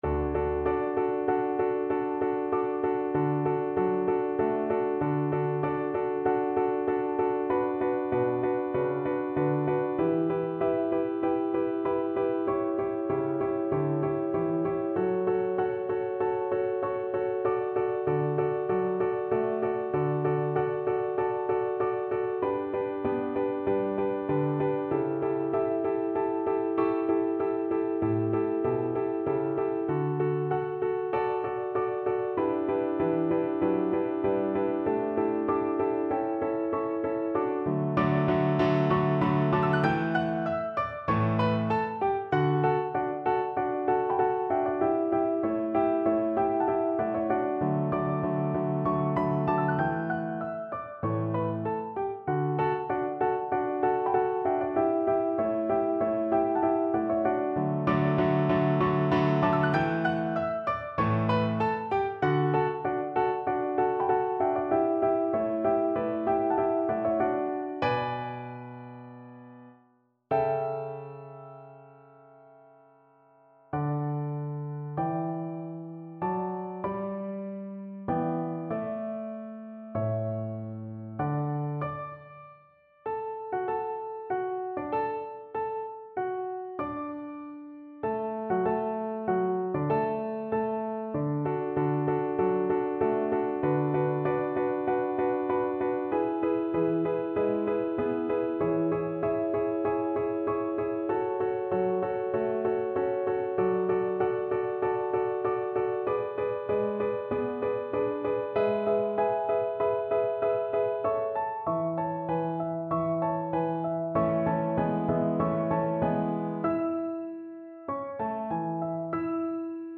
4/4 (View more 4/4 Music)
Allegro = c. 144 (View more music marked Allegro)
Voice  (View more Intermediate Voice Music)
Classical (View more Classical Voice Music)